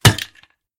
Kick_Can_UP.mp3